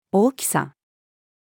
大きさ-female.mp3